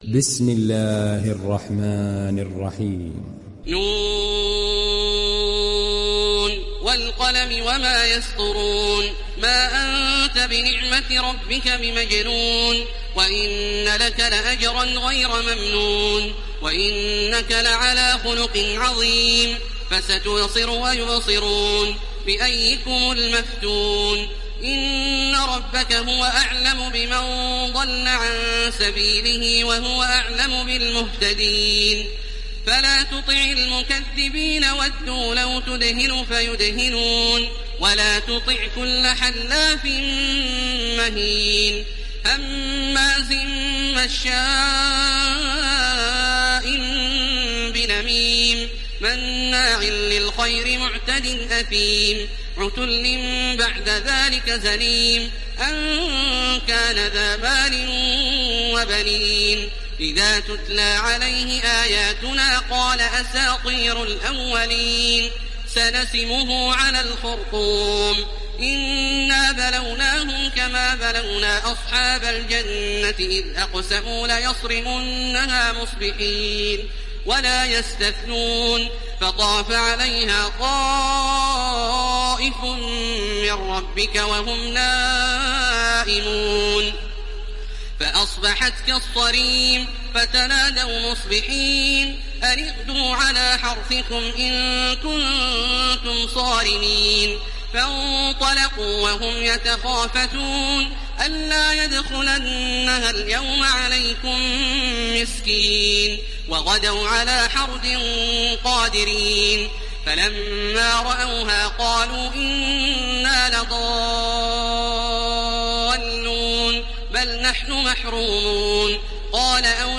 İndir Kalem Suresi Taraweeh Makkah 1430